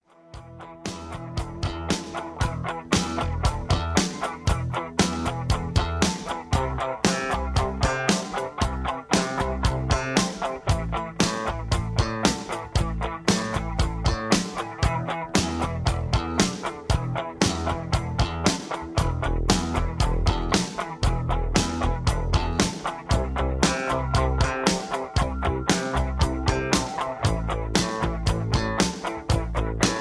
Key-Em) Karaoke MP3 Backing Tracks
Just Plain & Simply "GREAT MUSIC" (No Lyrics).